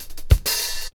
02DR.BREAK.wav